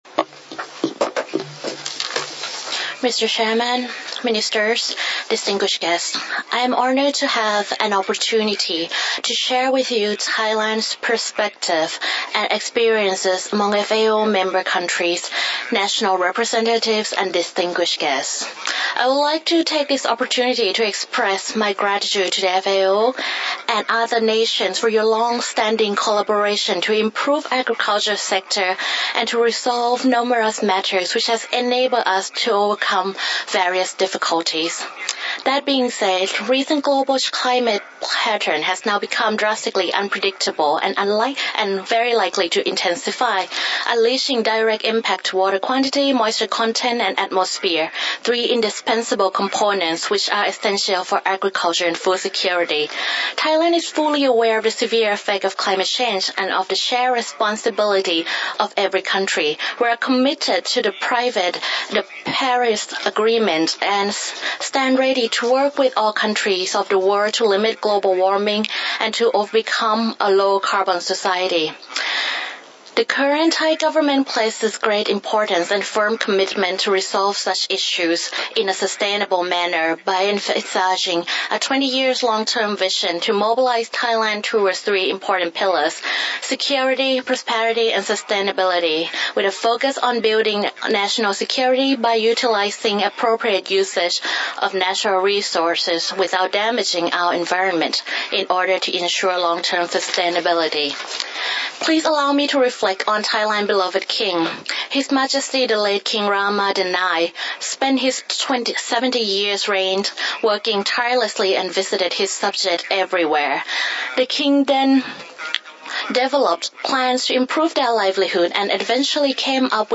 FAO Conference
Statements by Heads of Delegations under Item 9:
His Excellency General Chatchai Sarikulya, Minister for Agriculture and Cooperatives of the Kingdom of Thailand